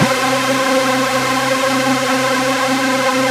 DDB2 One Shots 10 D. Rich Synth.wav